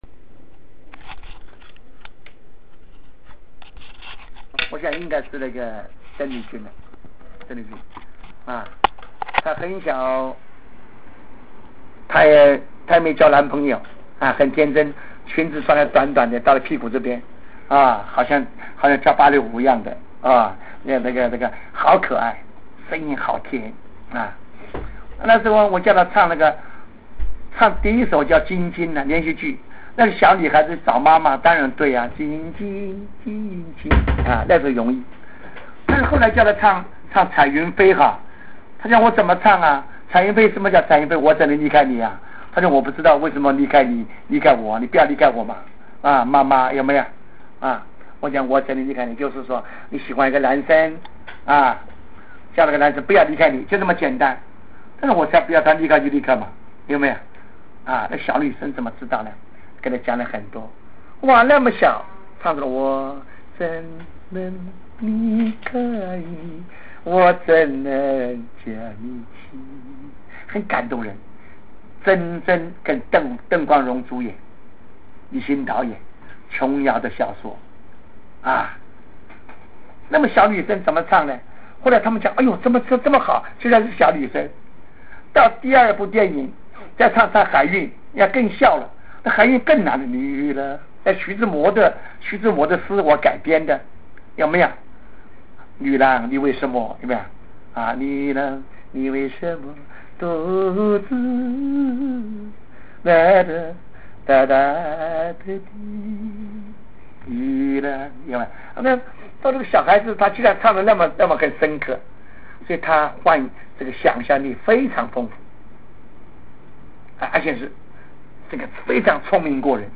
珍贵录音【左宏元回忆邓丽君】